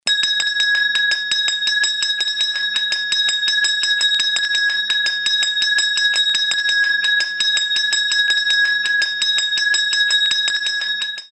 Звуки школьного колокольчика
Звонок на урок